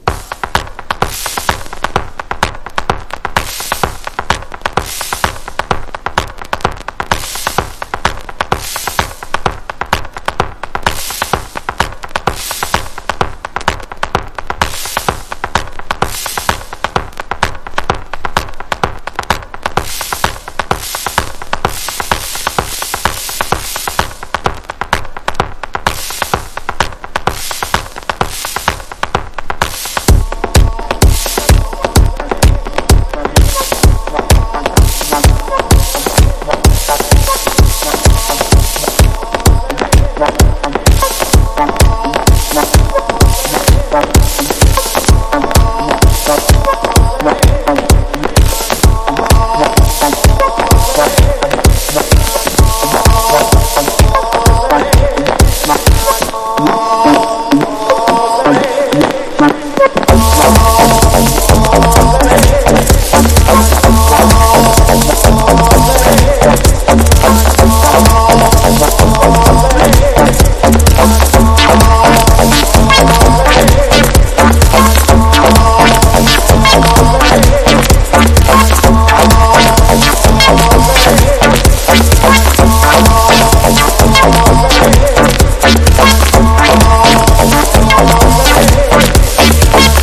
# ELECTRO HOUSE / TECH HOUSE